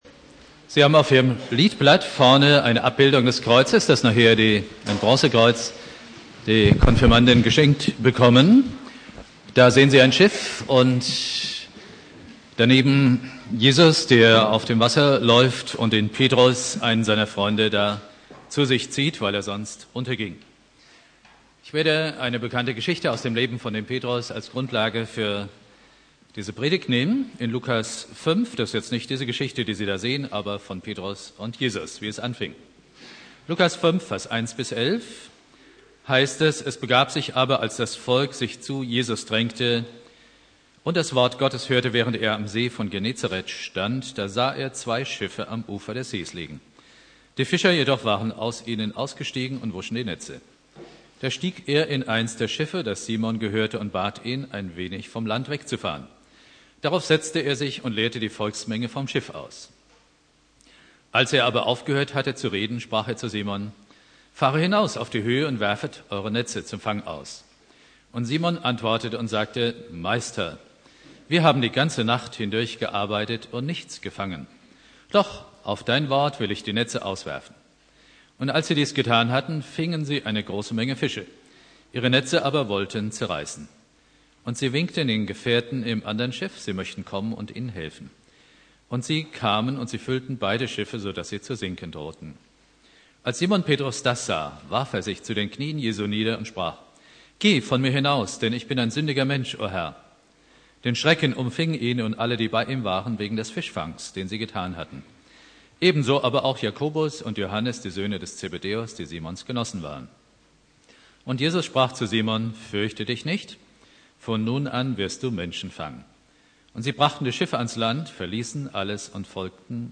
Predigt
Thema: Fischfang Petrus (Konfirmation Obertshausen) Bibeltext: Lukas 5,1-11 Dauer